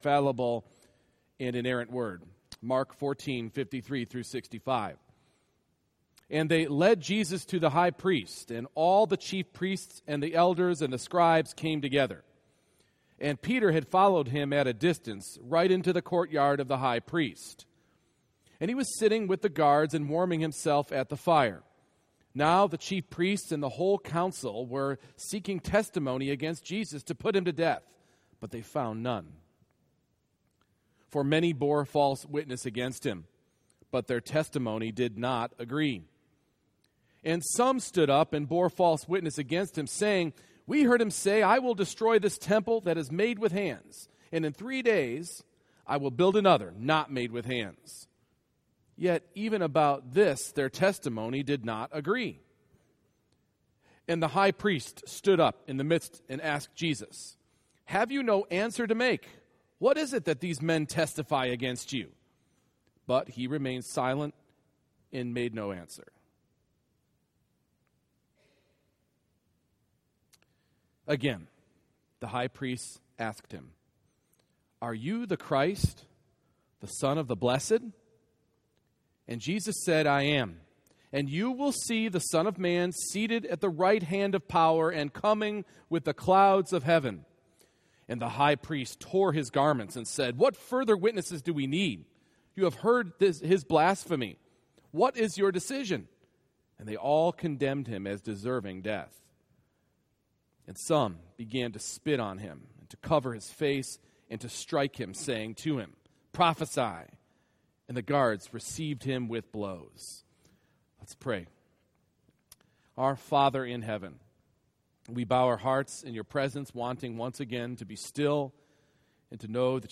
Mark 14:53-65 Service Type: Morning Worship The mock hearing of Jesus causes us to ask what is going on? and where’s the justice?